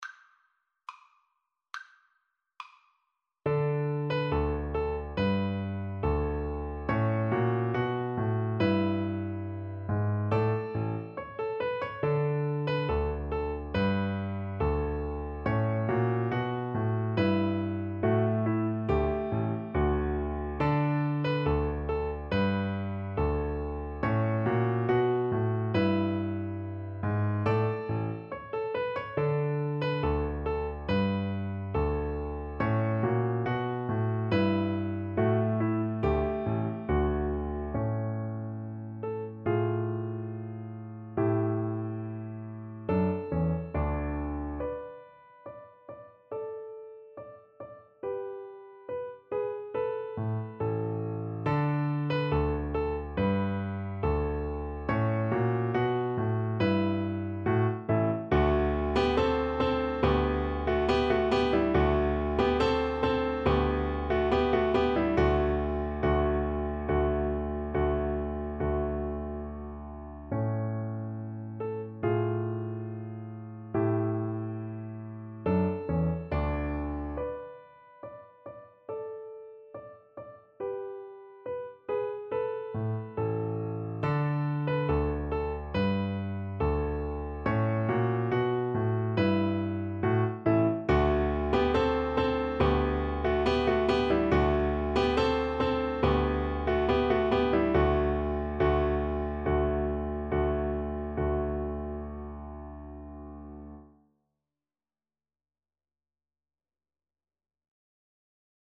2/4 (View more 2/4 Music)
Classical (View more Classical Viola Music)